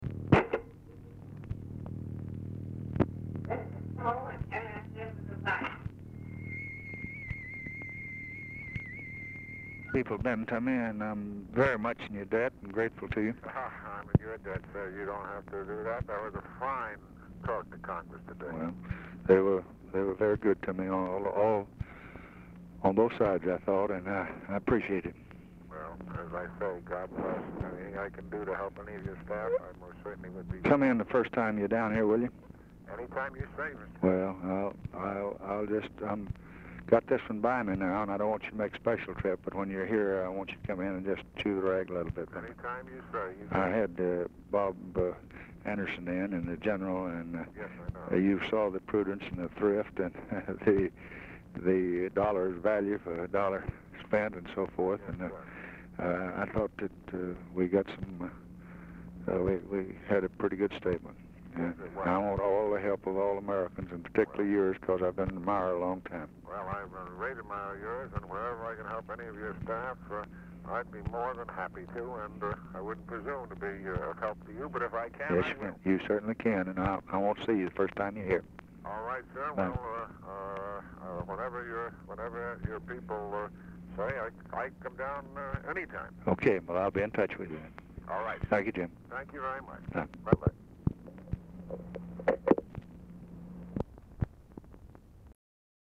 UNIDENTIFIED FEMALE SPEAKS AT BEGINNING OF CALL
Format Dictation belt
Location Of Speaker 1 Oval Office or unknown location
Specific Item Type Telephone conversation